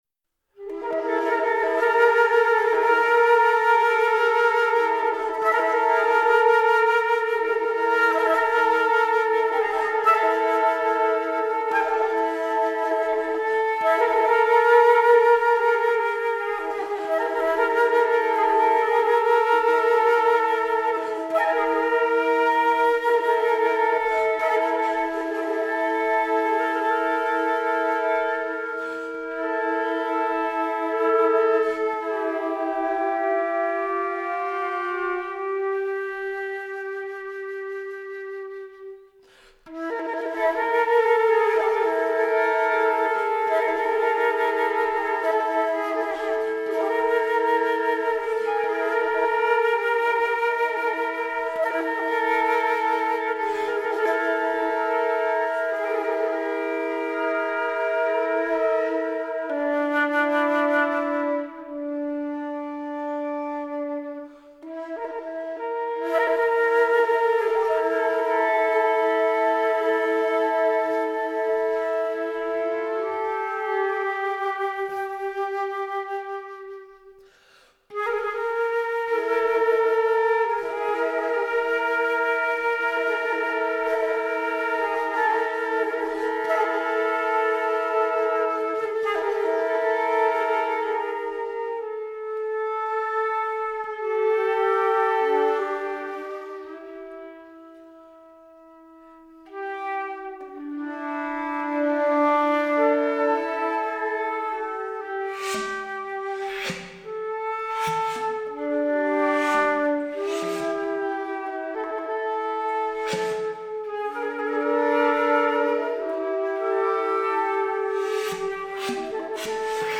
Mimetis para cuarteto de flautas